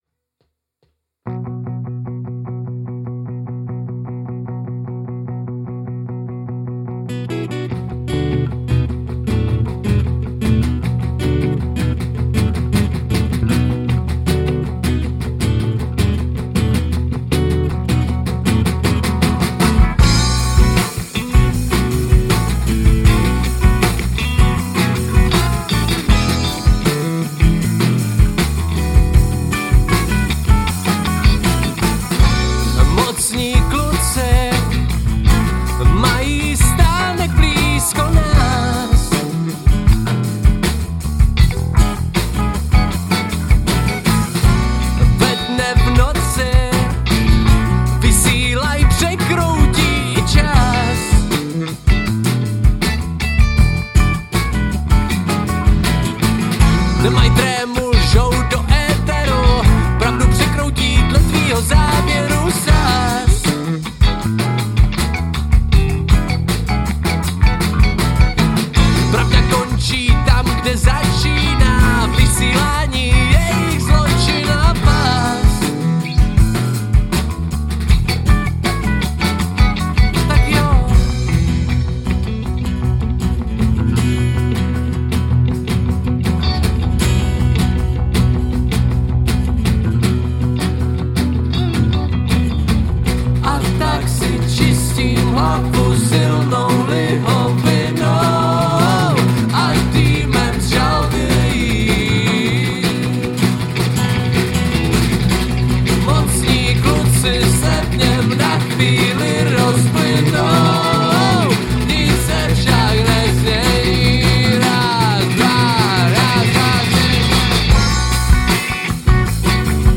Žánr: Rock
Záznam z živé - studiové - videosession.